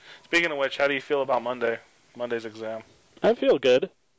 audio examples for Chapter 7: Expressing Positive Assessment
Uses in Conversation